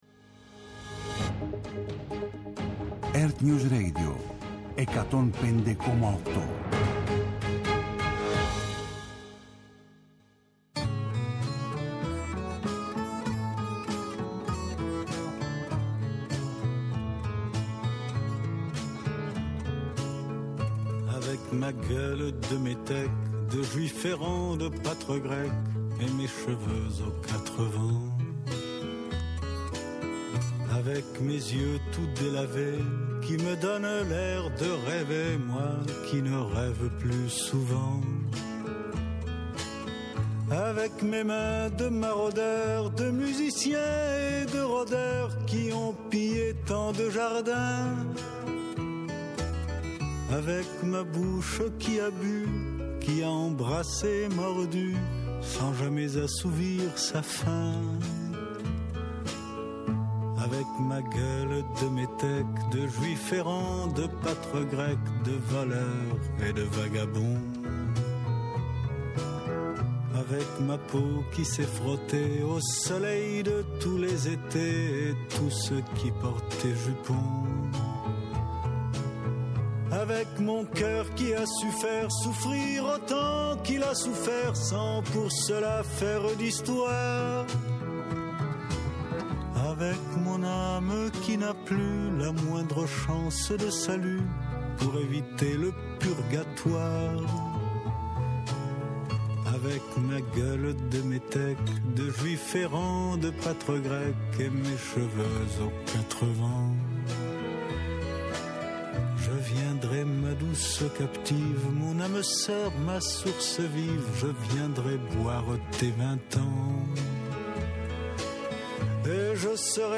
-Ο δημοσιογράφος Παύλος Τσίμας,
Η ΣΚΕΨΗ ΤΗΣ ΗΜΕΡΑΣ: Κική Δημουλά από το αρχείο της εκπομπής.